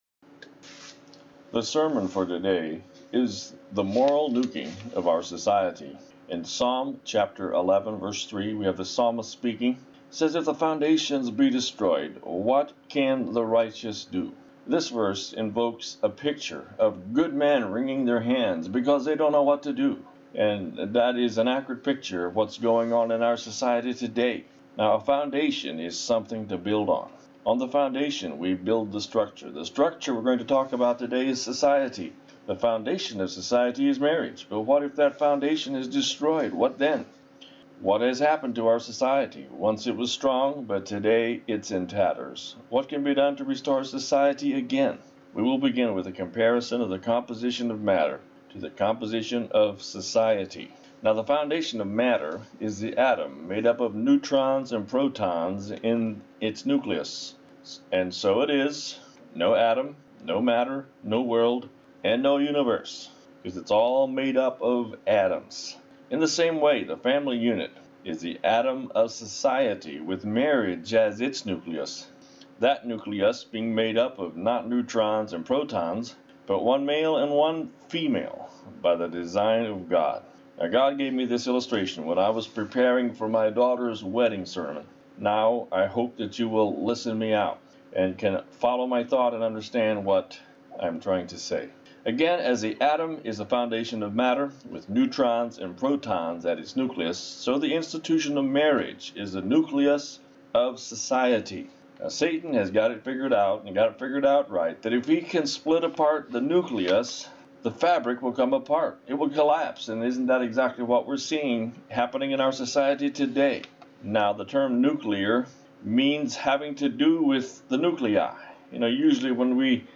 The Moral Nuking of Our Society-Audio Sermon.mp3